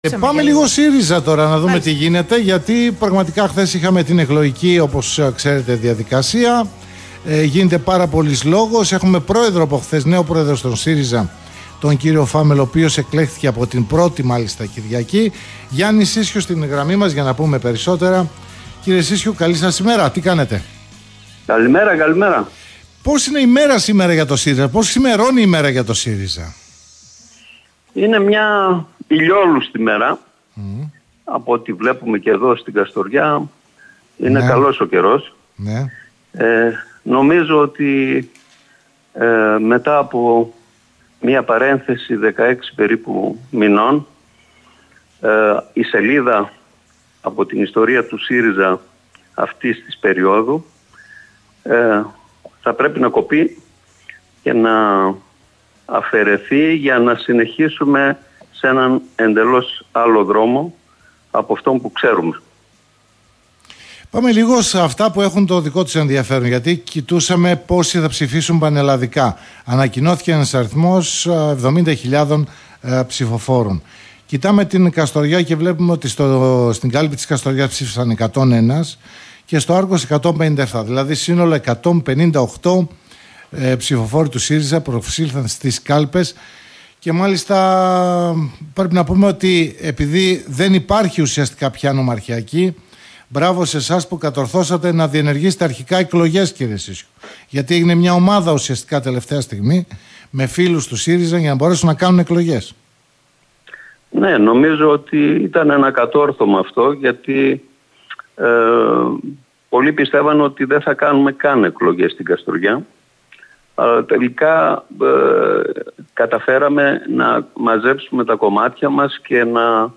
Στην Καστοριά και το Άργος Ορεστικό εγγράφηκαν 28 νέα μέλη” (συνέντευξη)